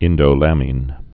(ĭndō-lămēn, ĭndō-lə-mēn)